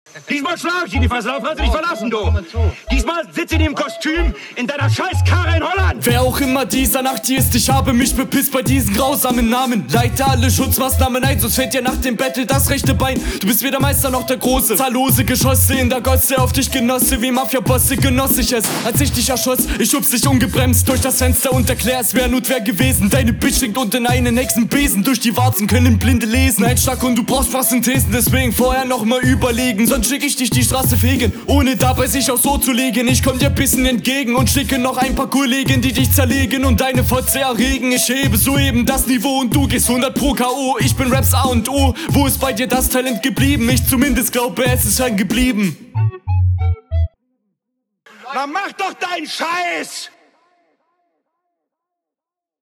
Seltsam stockender Beat.